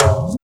2510R TOM.wav